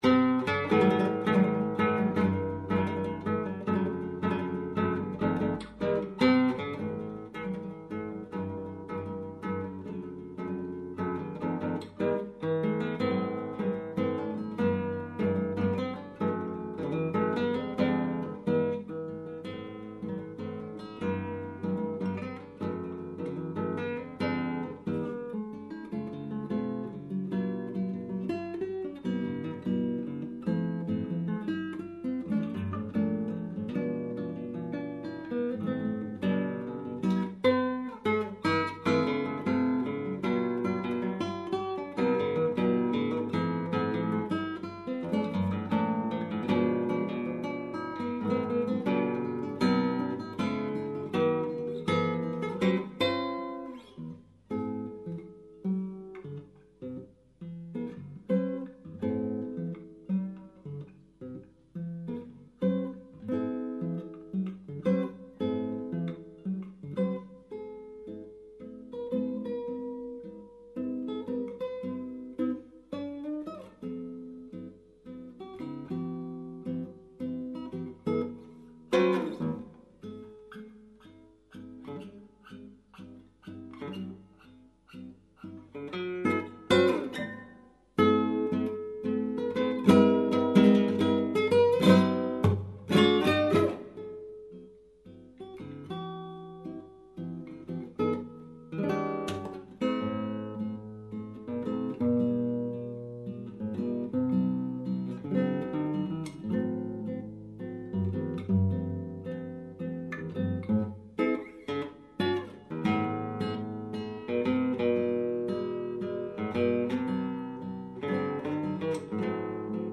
Chitarra sola